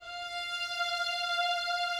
strings_065.wav